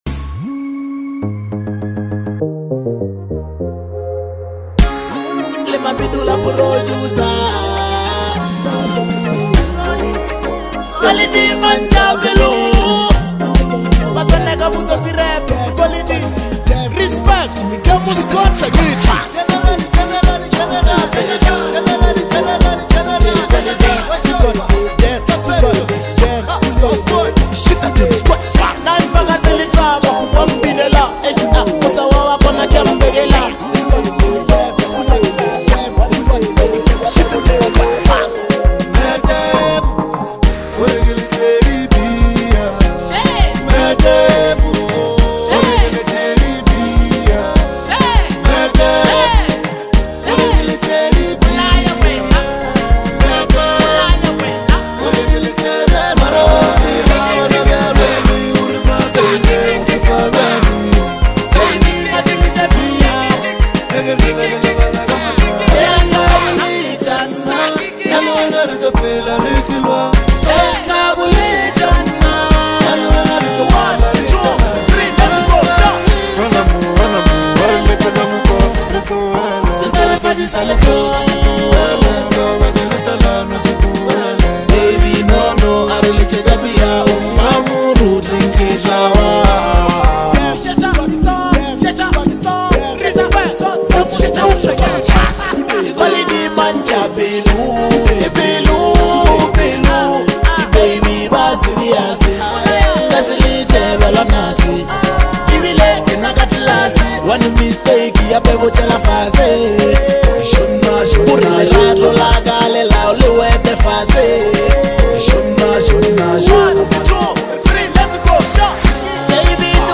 reflective, genre-blending record
Lekompo